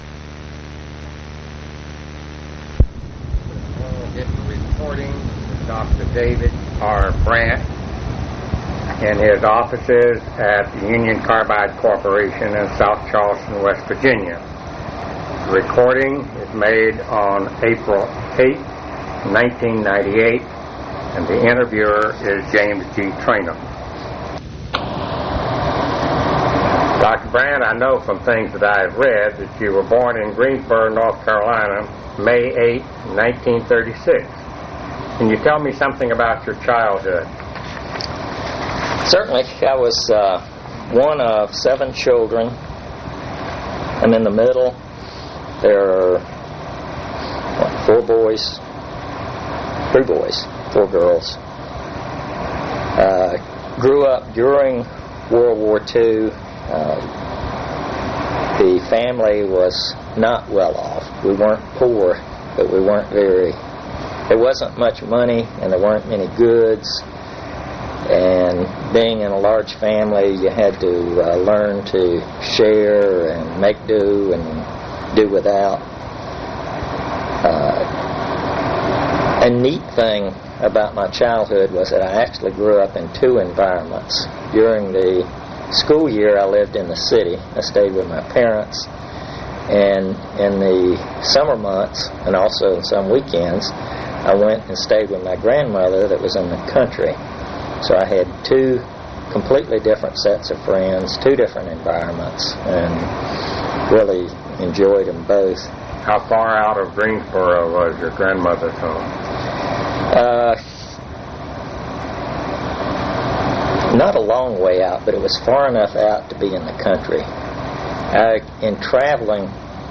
Complete transcript of interview